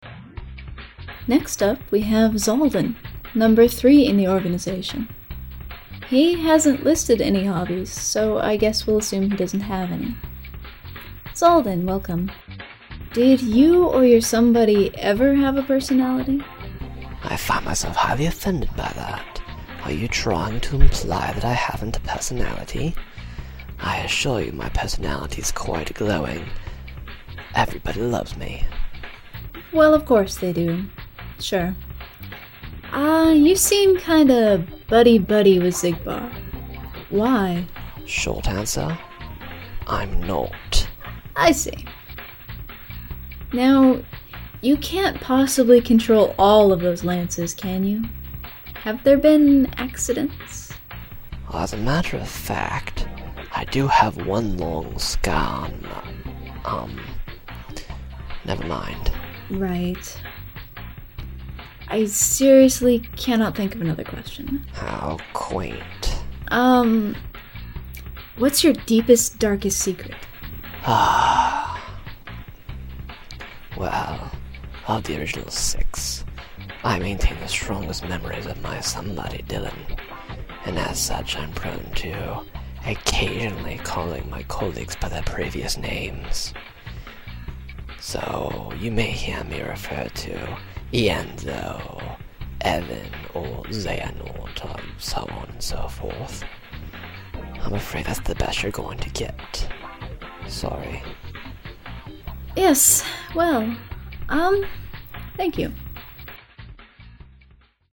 Character Interviews
03XaldinInterview.mp3